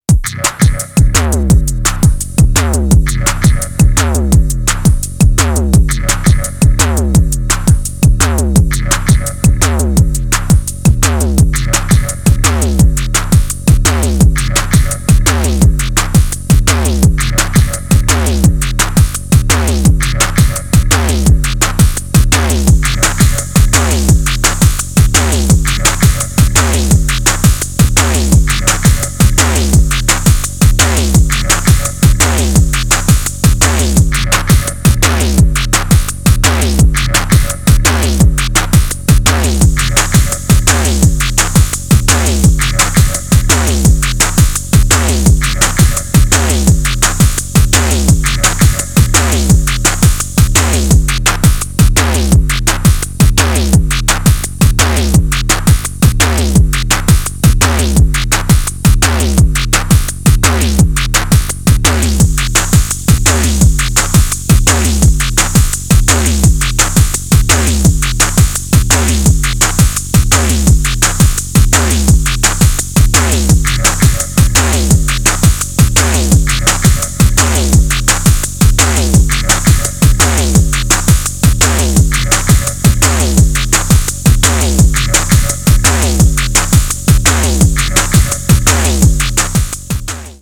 an uncompromising tour de force of face-melting techno
Track 1 Track 2 Track 3 Track 4 Techno